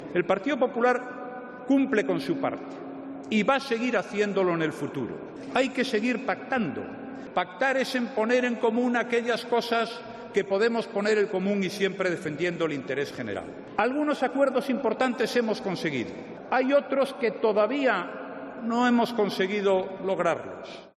El presidente del Gobierno, Mariano Rajoy, durante su intervención en la clausura del 15 Congreso Autonómico del PP